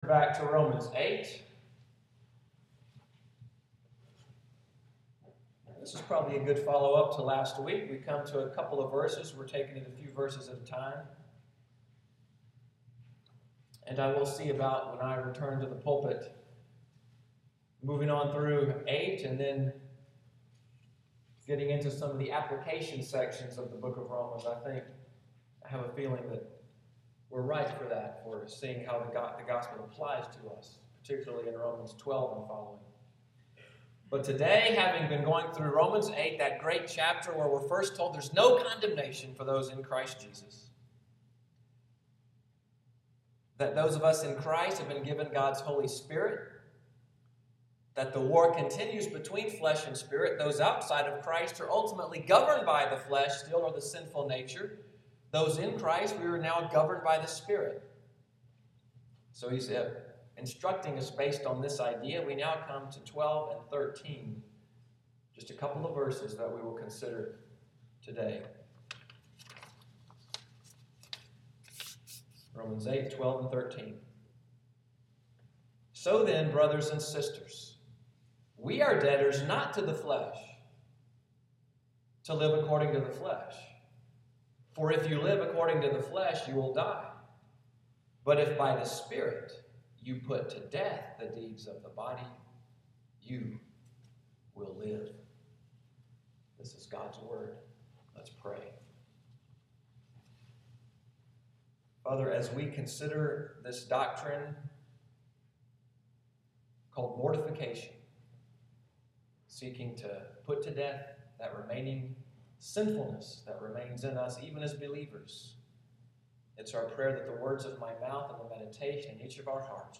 Sunday’s sermon, “Killing Sin,” July 5, 2015